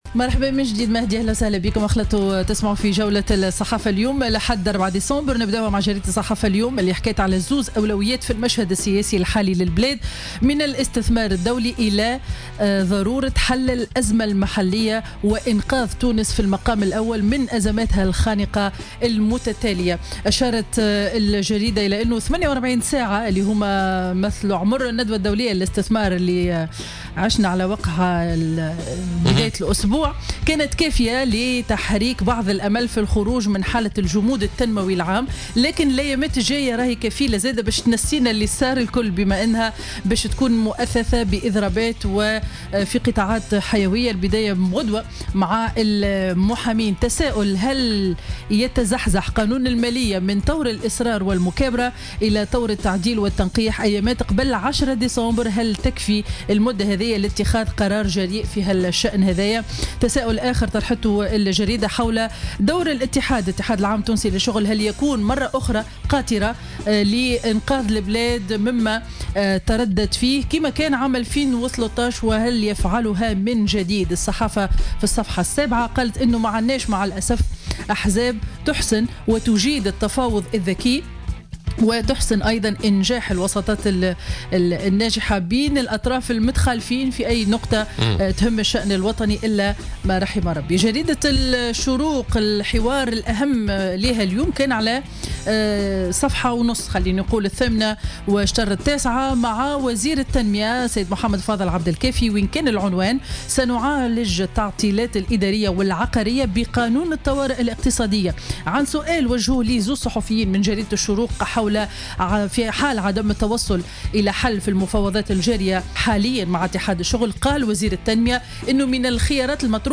Revue de presse du Dimanche 4 Décembre 2016